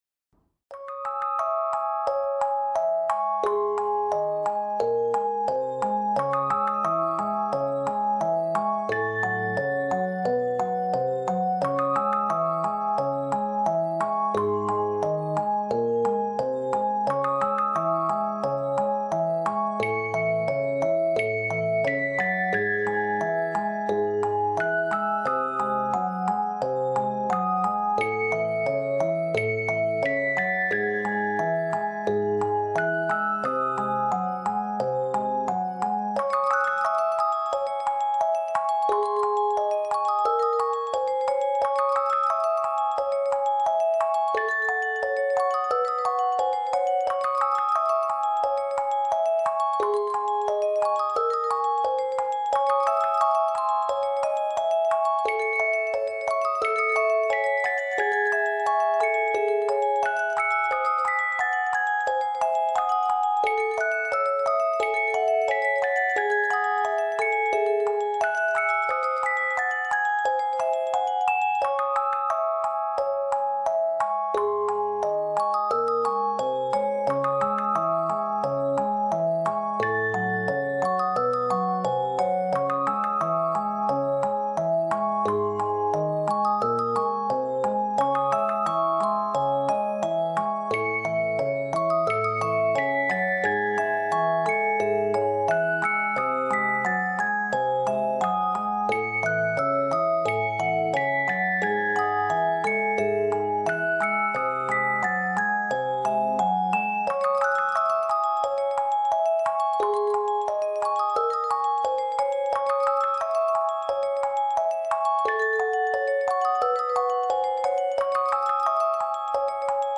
Здесь собраны лучшие композиции с нежным механическим звучанием, которые подойдут для релаксации, творчества или создания особой атмосферы.
3. Танцующая мелодия куклы